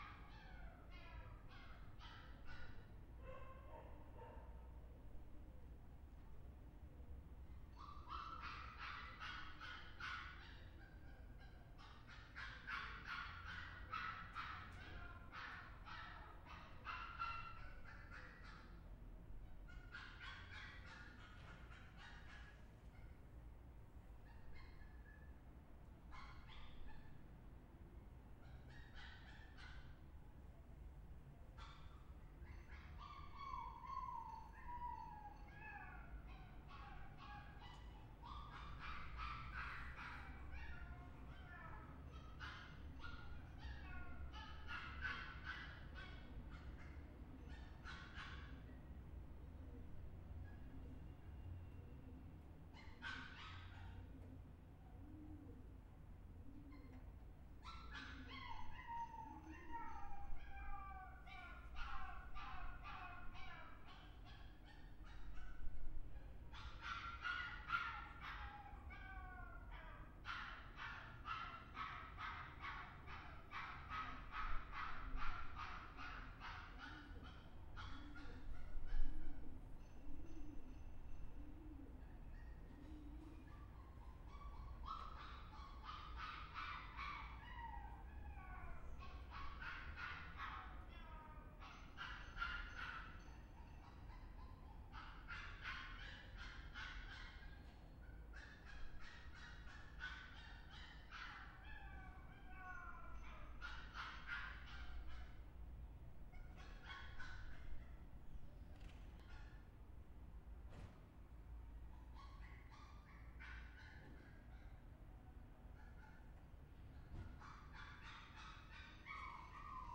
Zizkov, Jeronymova street, backyard. The dog was barking like that whole morning.